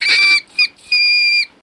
Mink Scream Triple Call